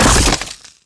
rifle_hit_stone1.wav